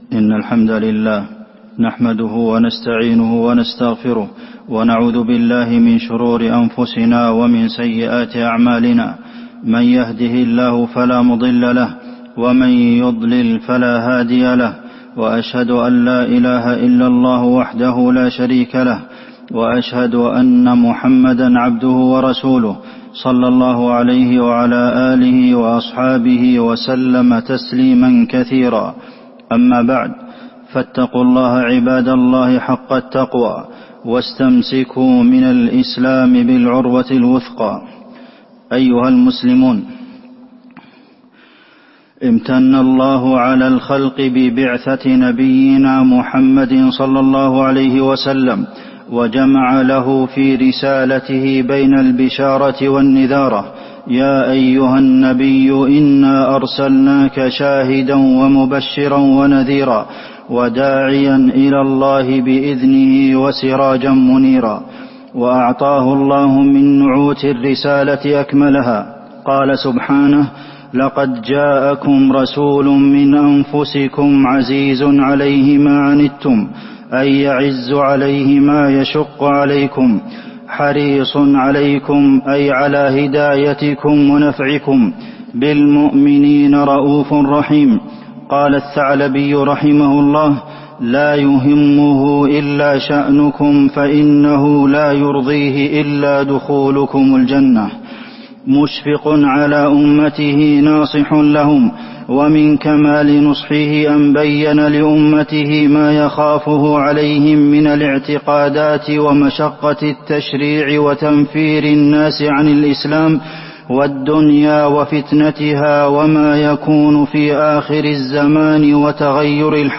تاريخ النشر ٢٣ جمادى الآخرة ١٤٤٢ هـ المكان: المسجد النبوي الشيخ: فضيلة الشيخ د. عبدالمحسن بن محمد القاسم فضيلة الشيخ د. عبدالمحسن بن محمد القاسم ماخافه النبي عليه الصلاة والسلام على أمته